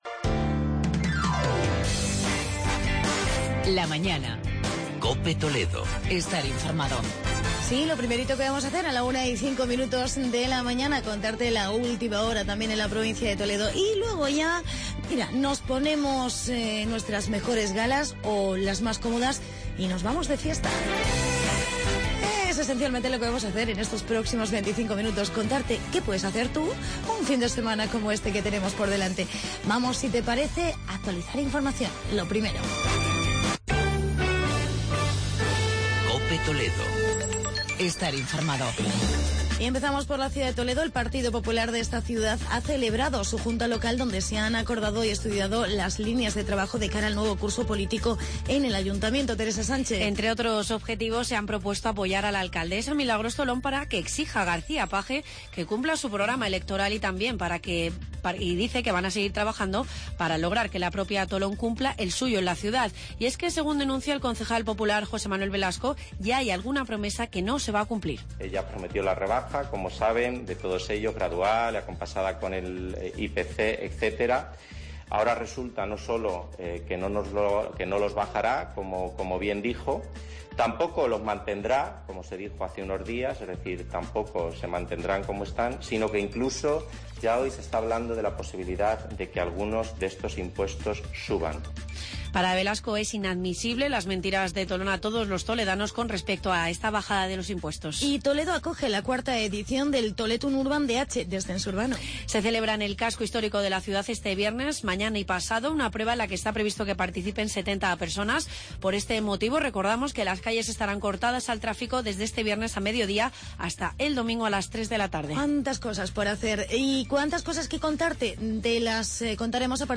Hablamos de las fiestas de Segurilla y Calera y Chozas con sus alcaldes y agenda cultural de los pueblos de Toledo.